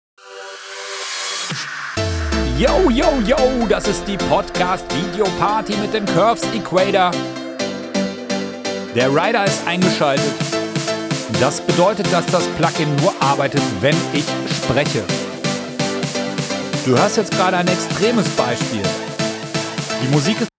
Dafür habe ich ein Projekt vorbereitet – mit einer Musikspur und einer Sprachspur.
Dafür muss ich die Quelle abspielen – und du hörst die Mischung zunächst ohne den Curves Equator.
Mix ohne Curves Equator
mix-ohne-curves-equator.mp3